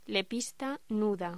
Locución: Lepista nuda
voz